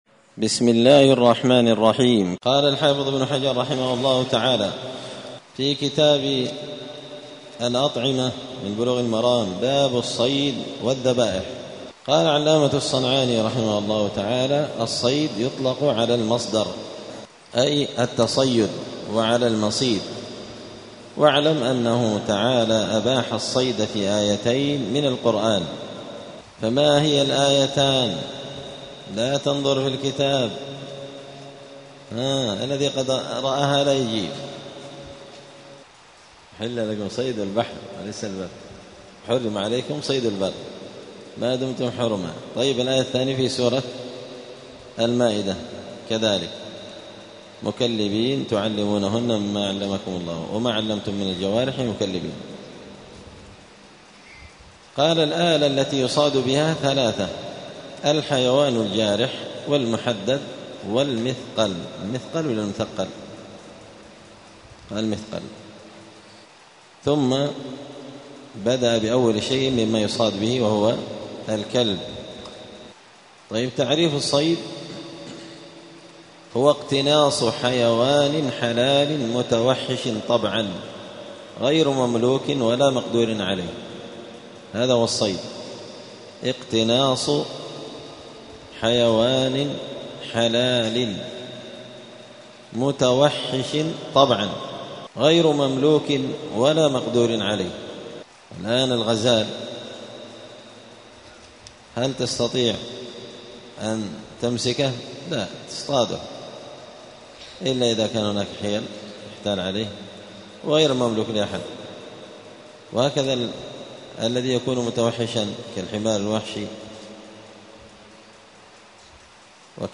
*الدرس الثامن (8) {باب الصيد والذبائح صيد الكلب المعلم}*